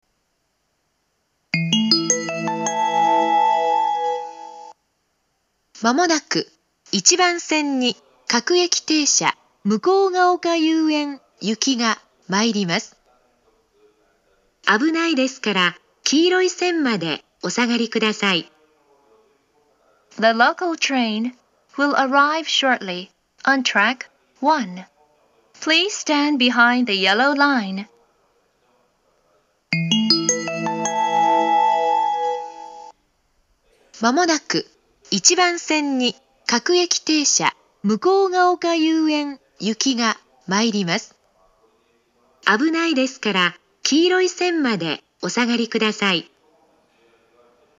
１番線接近放送
１番線到着放送
車両の乗降促進メロディーで発車します。